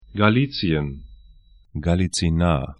Galizien ga'li:tsĭən Halychyna galitsi'na: uk Gebiet / region 49°40'N, 23°52'E